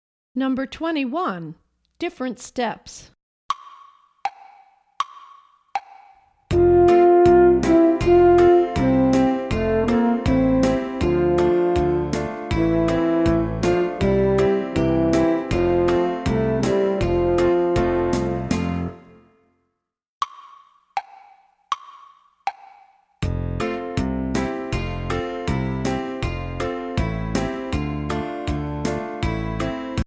Voicing: Baritone Saxophone